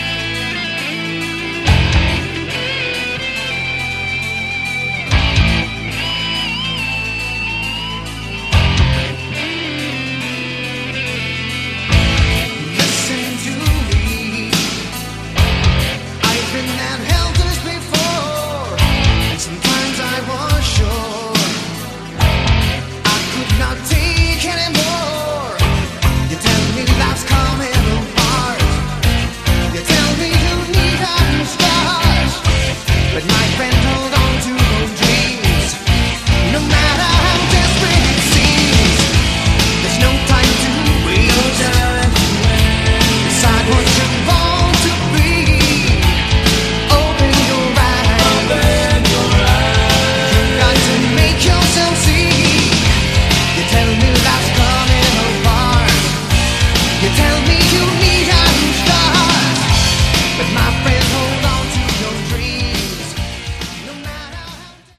Category: AOR
vocals, bass
drums, backing vocals
guitars, backing vocals
keyboards, backing vocals